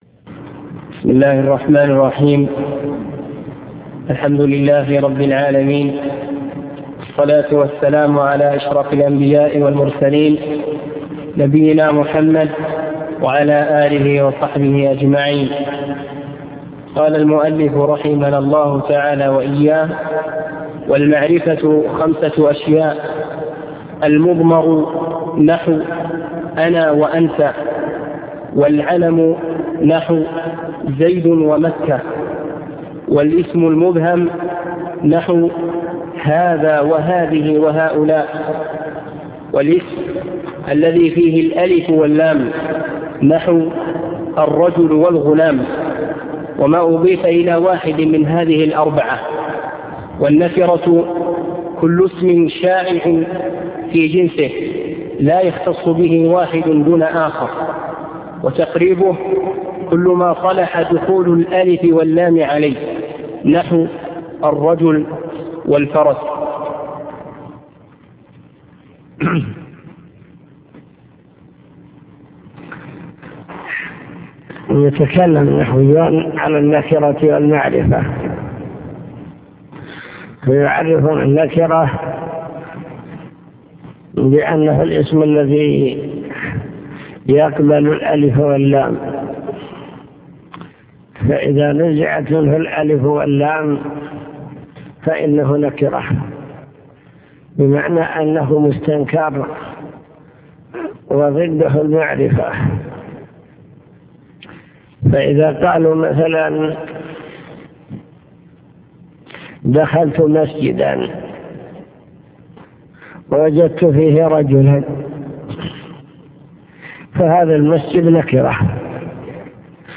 المكتبة الصوتية  تسجيلات - كتب  شرح كتاب الآجرومية النكرة والمعرفة